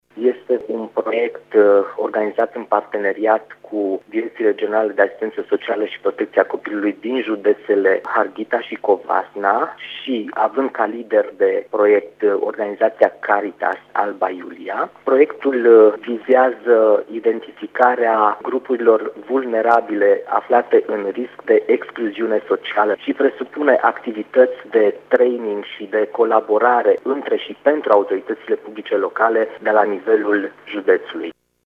CJ Mureș a aprobat, ieri, o rectificare de buget de 32.000 de lei, sumă ce va fi utilizată de Direcția de Asistență Socială și Protecția Copilului Mureș, pentru prevenirea acestui fenomen. Banii vin printr-un proiect câştigat de Caritas Alba Iulia, care include judeţele Mureş, Harghita şi Covasna, a precizat secretarul CJ Mureş, Paul Cosma: